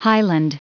Prononciation du mot highland en anglais (fichier audio)
Prononciation du mot : highland